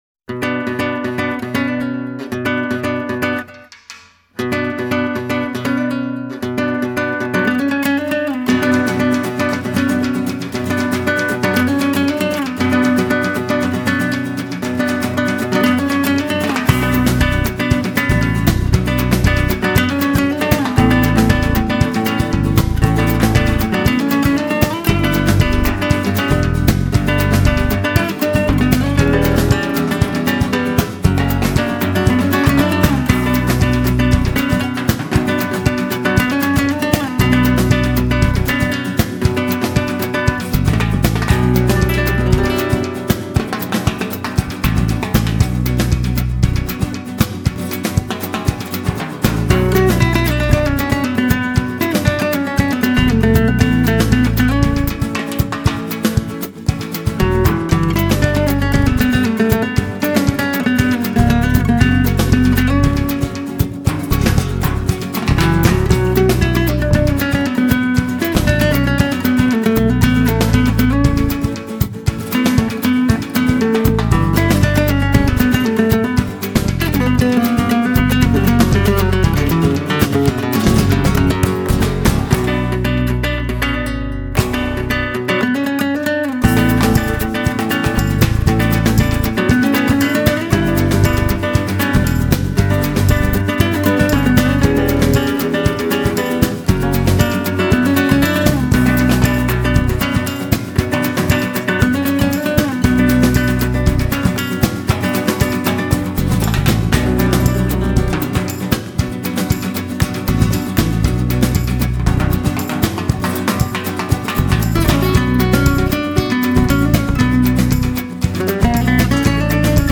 类型:Flamenco